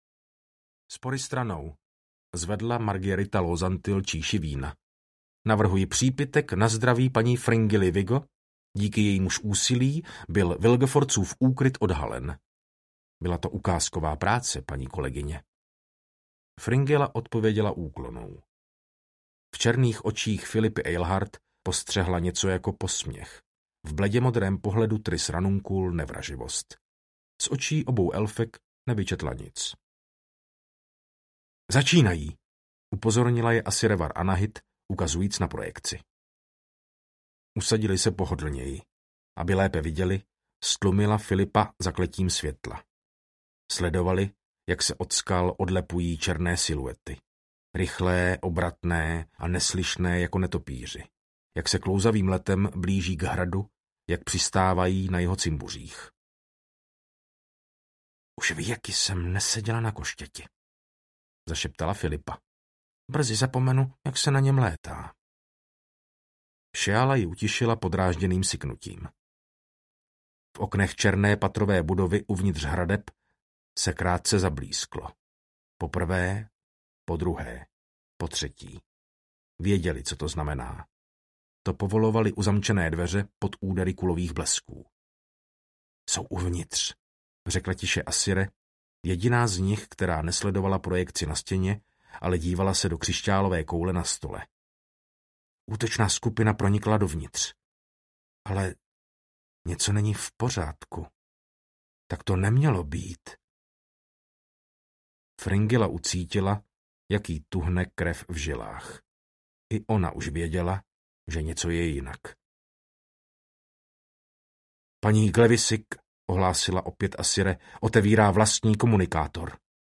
Audiobook
Read: Martin Finger